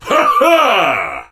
surge_kill_vo_01.ogg